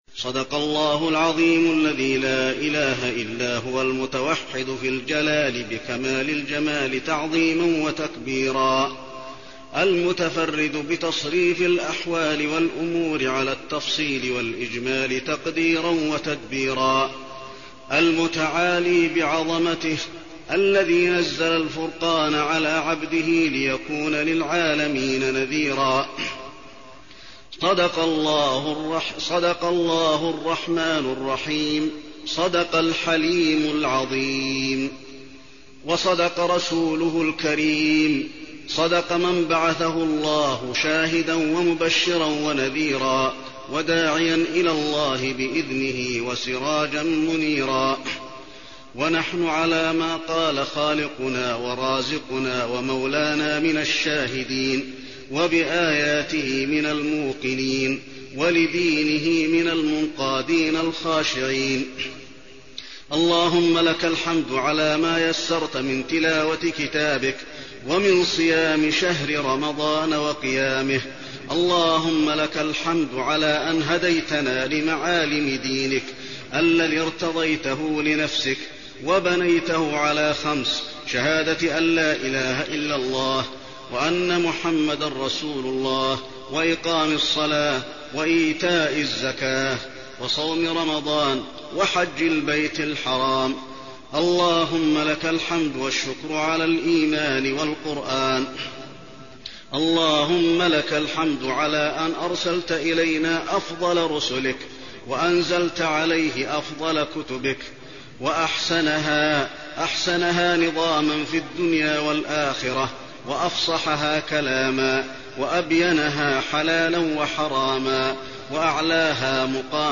دعاء ختم القرآن
المكان: المسجد النبوي دعاء ختم القرآن